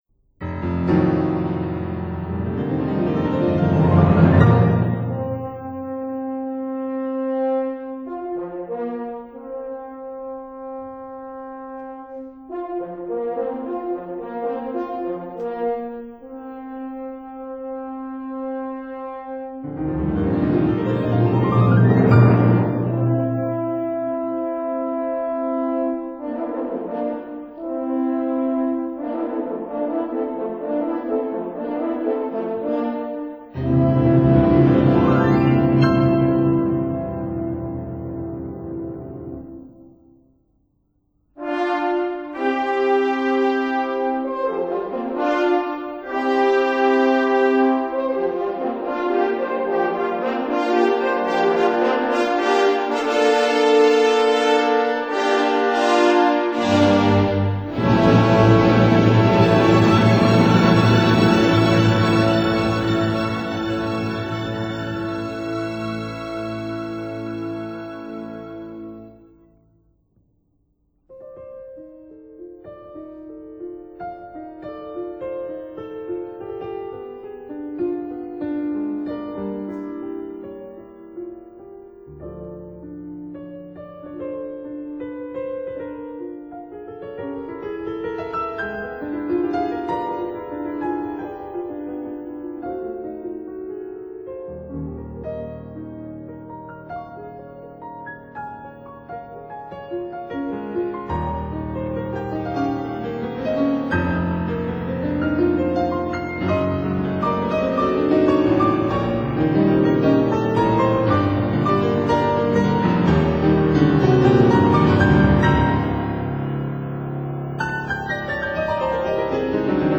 for cello and orchestra